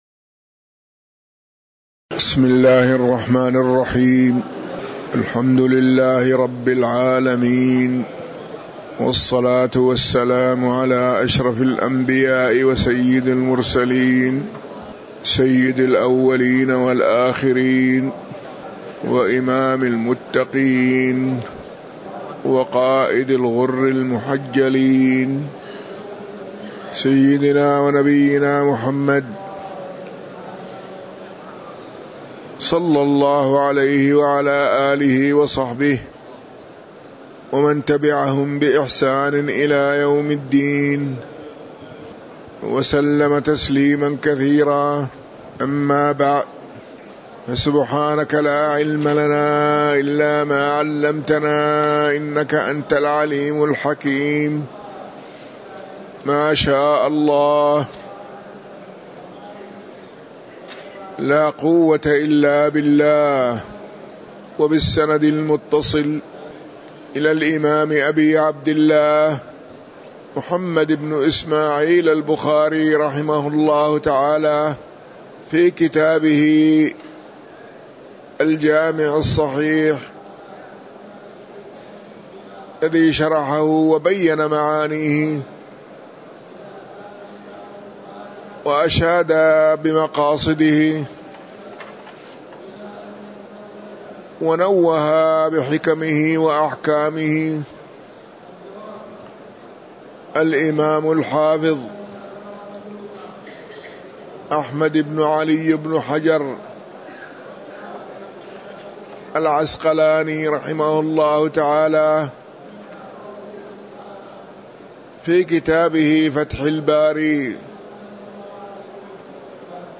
تاريخ النشر ٢٦ ذو الحجة ١٤٤٣ هـ المكان: المسجد النبوي الشيخ